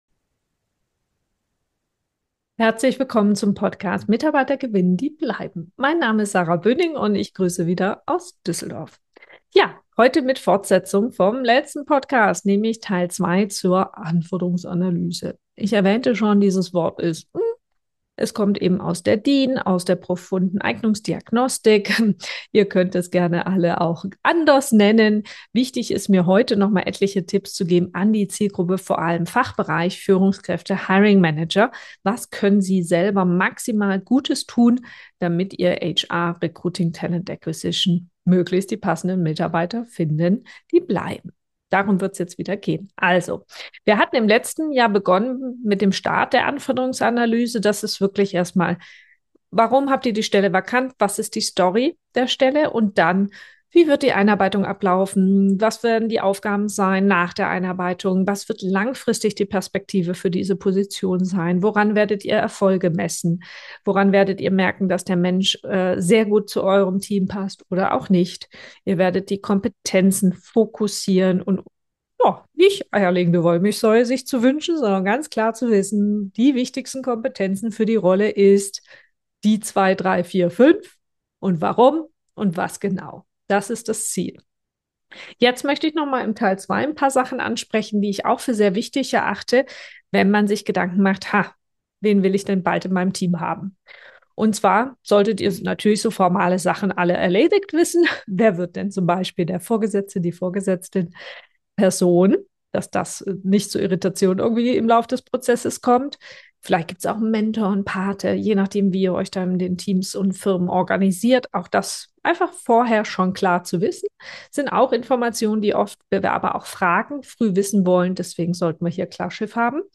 In dieser Solo-Folge geht’s um einen zentralen Schritt im Recruiting – die Anforderungsanalyse. Wenn du als Führungskraft gerade gezielt neue Mitarbeitende suchst, solltest du nicht mit dem Texten der Stellenanzeige starten – sondern mit einer klaren Reflexion: Wen genau brauchen wir eigentlich?